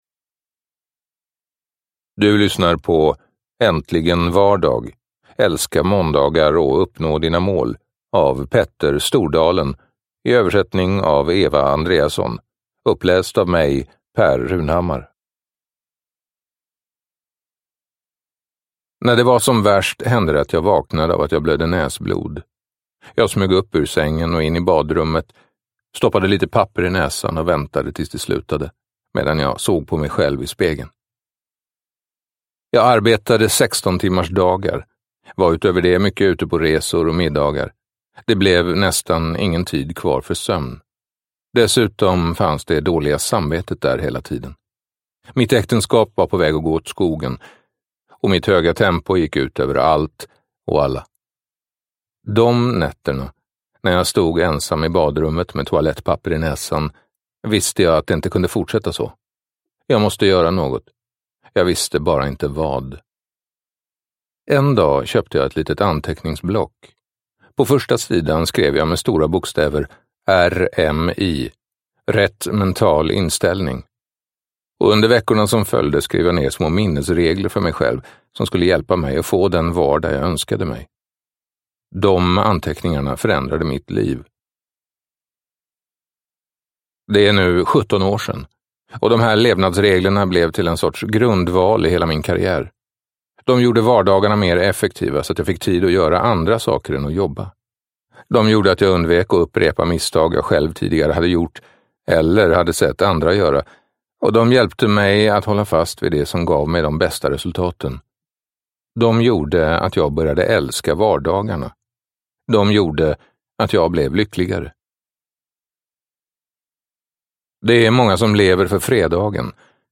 Äntligen vardag : älska måndagar i med- och motgång – Ljudbok – Laddas ner